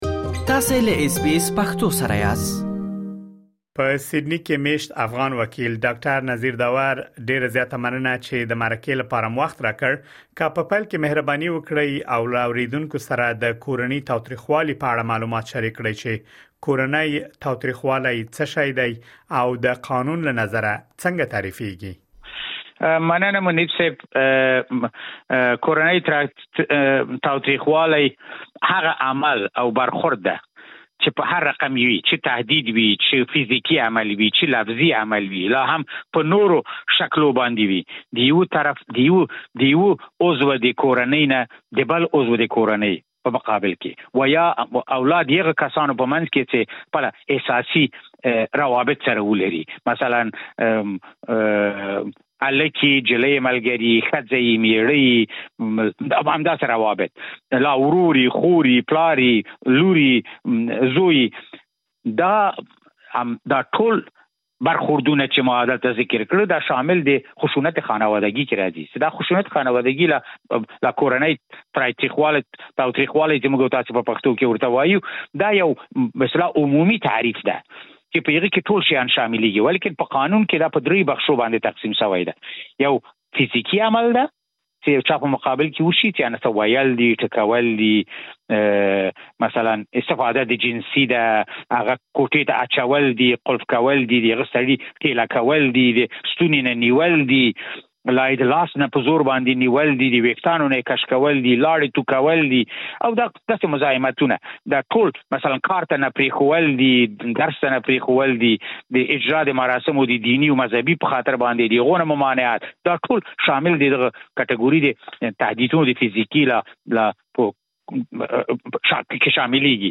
زموږ د فېسبوک پاڼې له لارې د مرکو او راپورونه اورېدولو ته دوام ورکړئ.